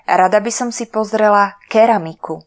Slovak voice announciation